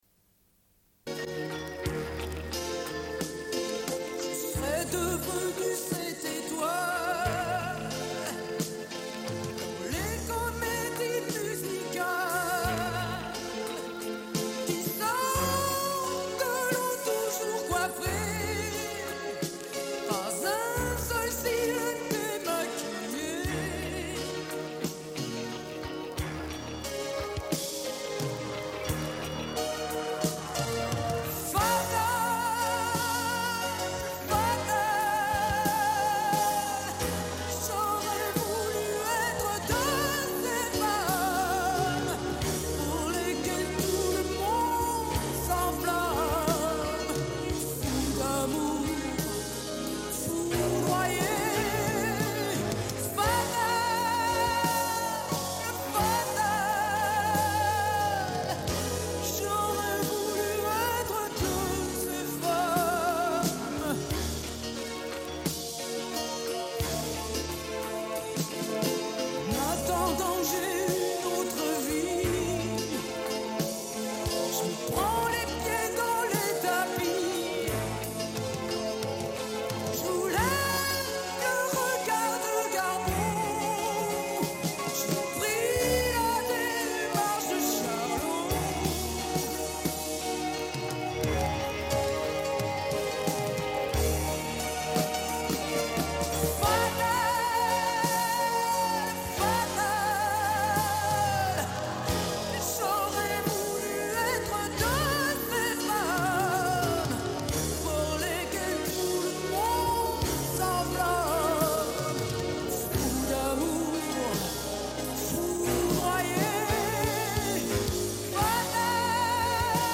Une cassette audio, face A00:31:30
Radio